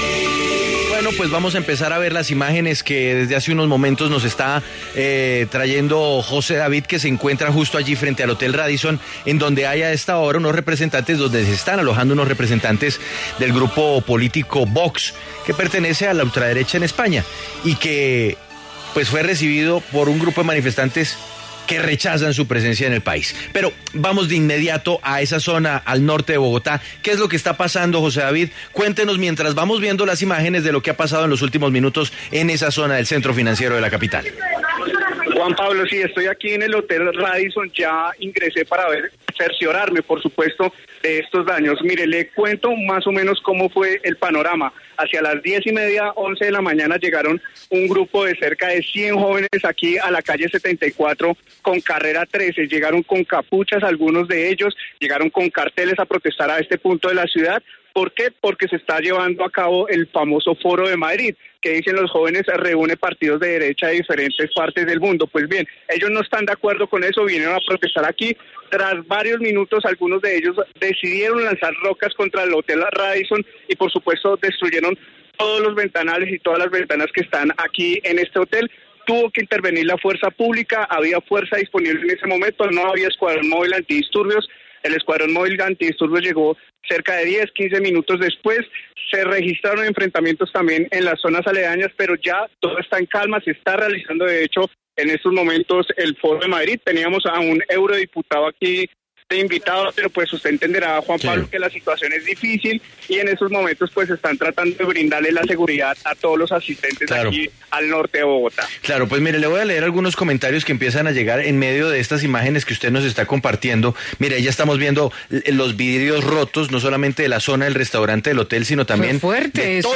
Estas son las declaraciones del eurodiputado del Partido Vox, Hermann Tertsch: